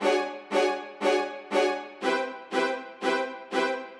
Index of /musicradar/gangster-sting-samples/120bpm Loops
GS_Viols_120-D.wav